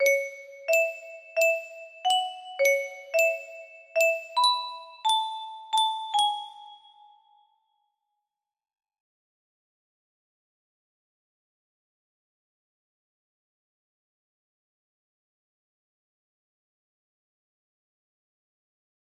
Rif music box melody